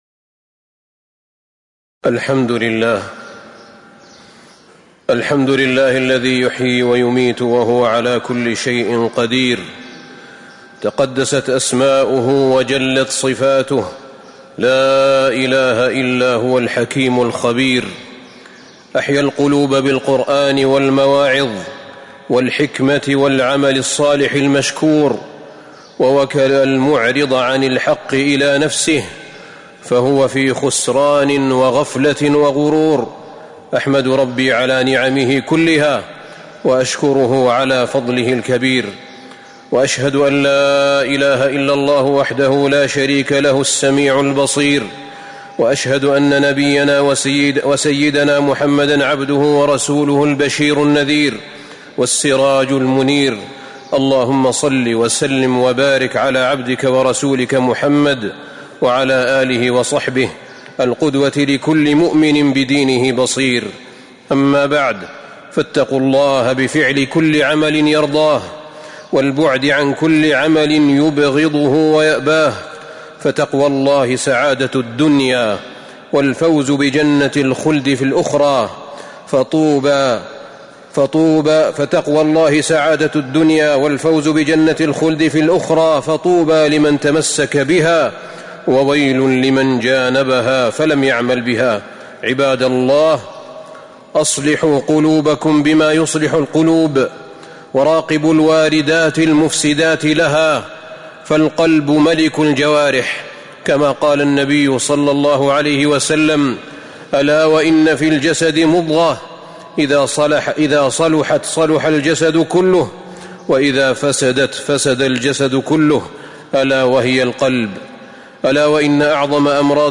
تاريخ النشر ٣٠ جمادى الآخرة ١٤٤٥ هـ المكان: المسجد النبوي الشيخ: فضيلة الشيخ أحمد بن طالب بن حميد فضيلة الشيخ أحمد بن طالب بن حميد الغفلة أسبابها وعلاجها